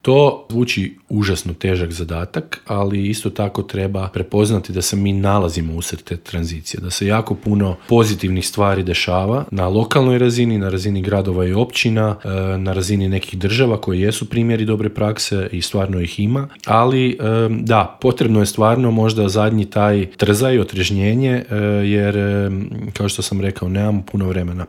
Intervju Media servisa